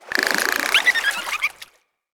SFX